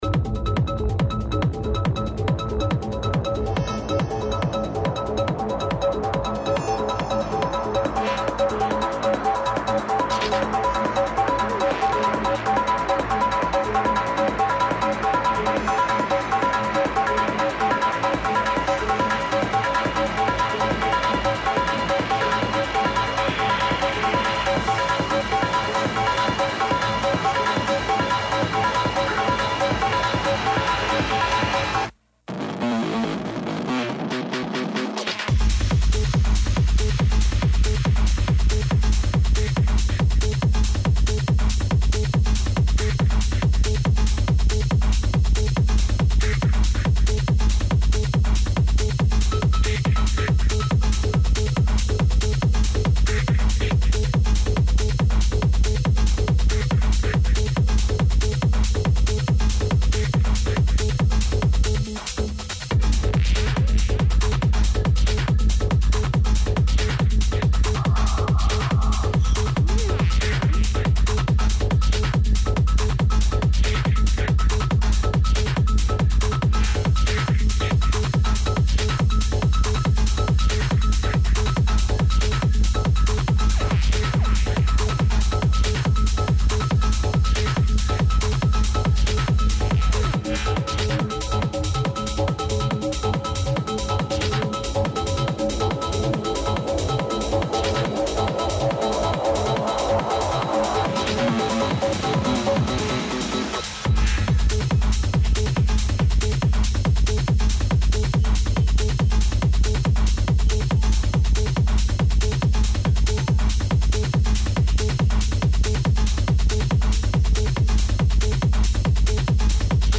deep, hypnotic techno set